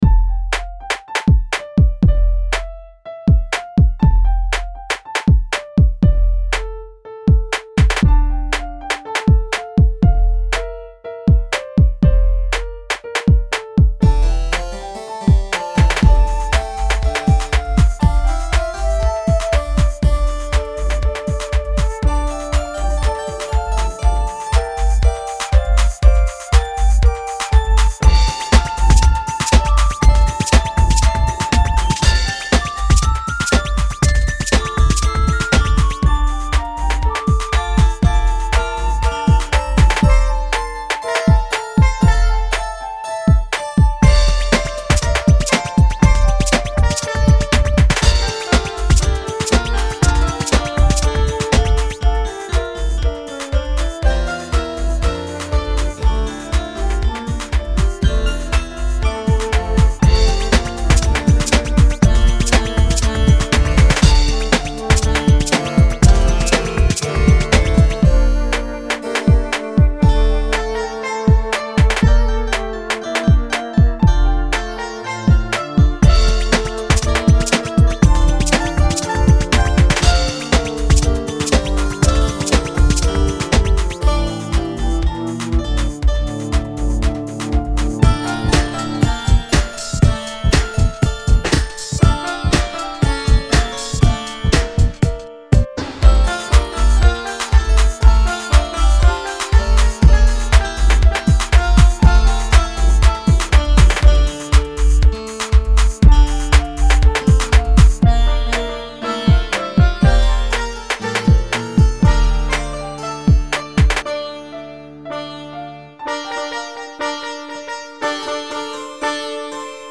Posted in Classical, Dubstep, Other Comments Off on